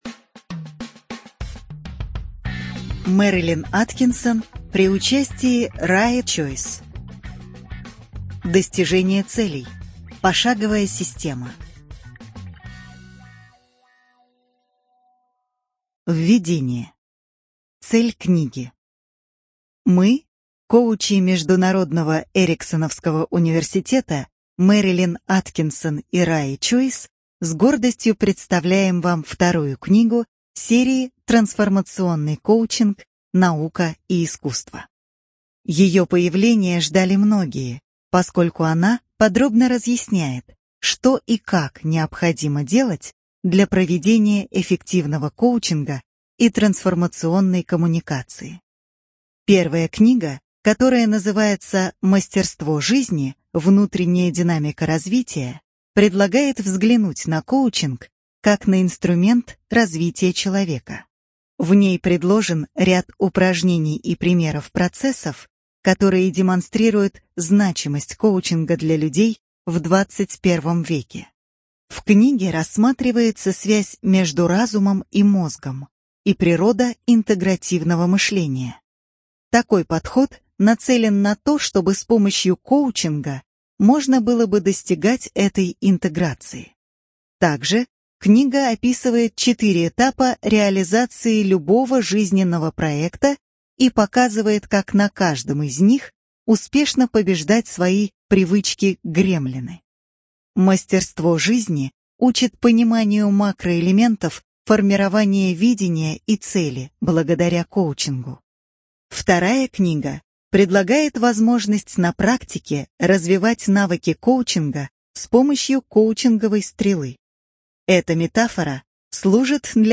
БиблиотекаКниги → Достижение целей. Пошаговая система